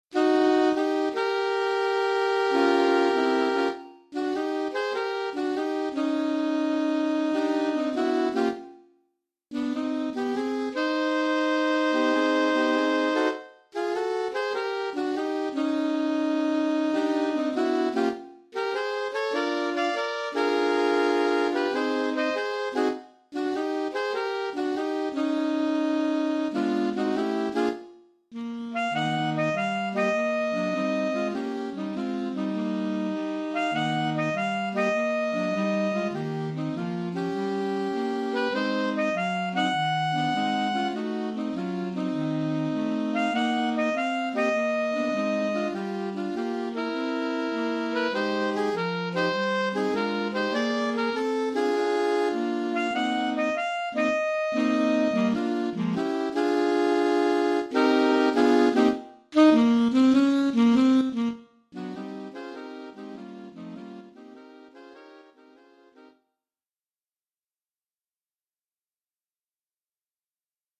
Oeuvre pour quatuor de saxophones
(saxophones altos 1, 2, 3 et 4).